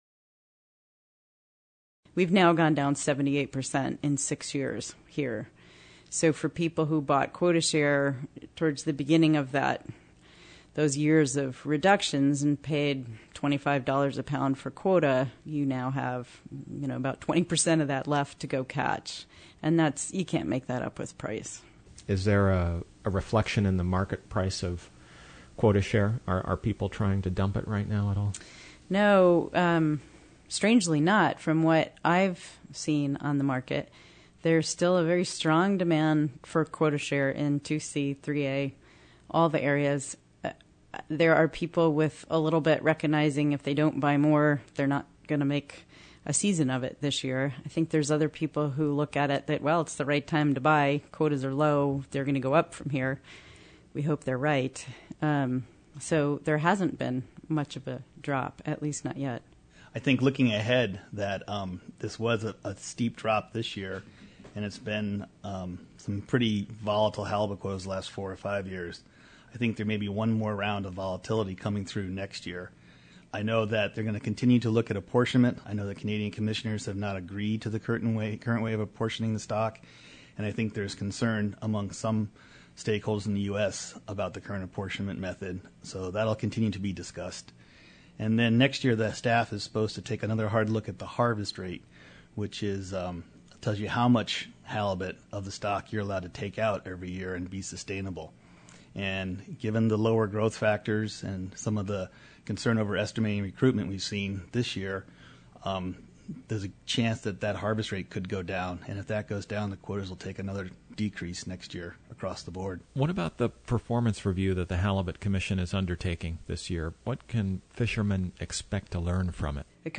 Interview: Lower harvest rate may bring future cuts, pt. 2